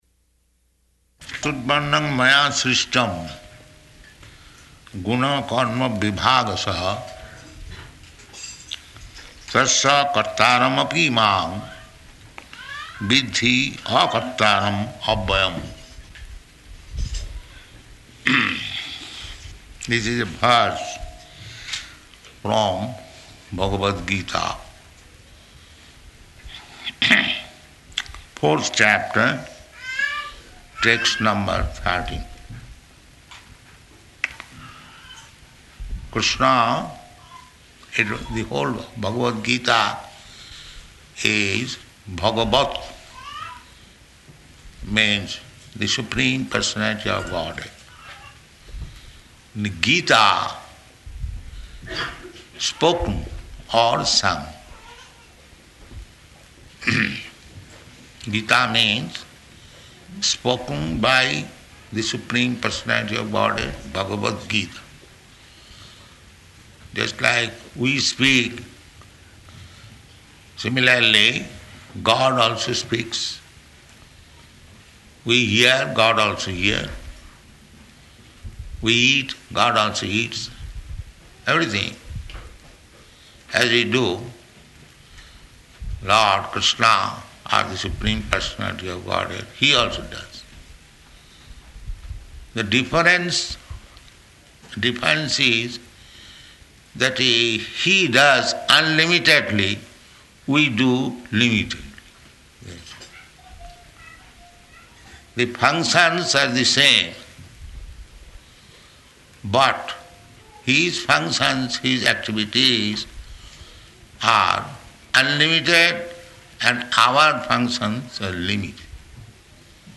Location: Johannesburg